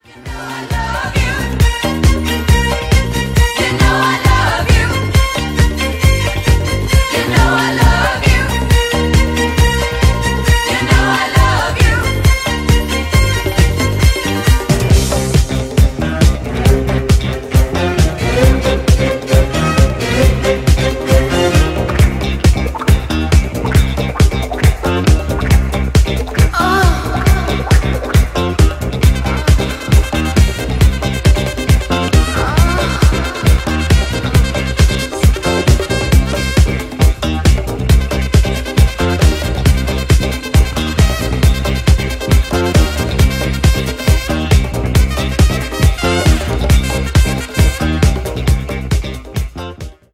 Bringing you soulful, energetic dancefloor moments.
scorching disco edits